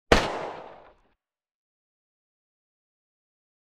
pistol.wav